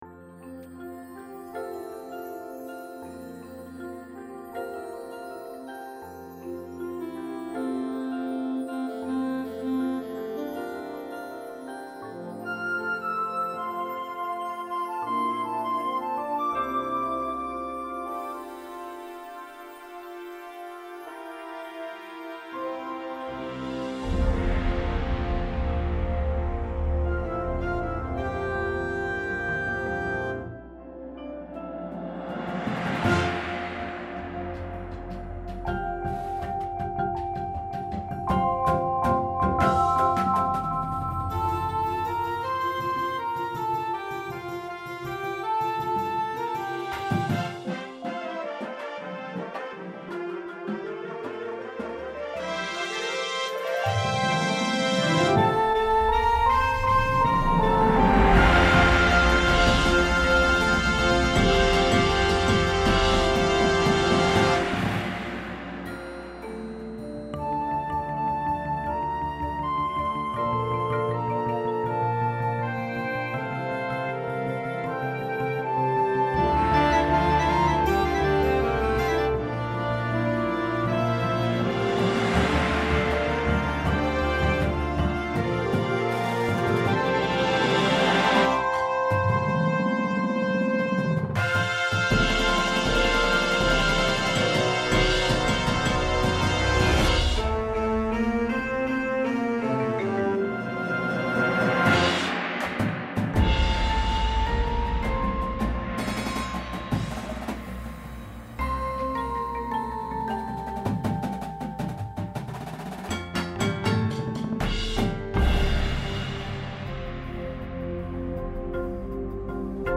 INSTRUMENTATION:
• Flute
• Clarinet 1, 2
• Alto Sax 1, 2
• Trumpet 1
• Horn in F
• Trombone 1, 2
• Tuba
• Snare Drum
• Sound Effect Samples
• Marimba – Two parts
• Vibraphone – Two parts
• Glockenspiel/Xylophone